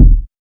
KICK.39.NEPT.wav